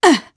Demia-Vox_Damage_jp_01_b.wav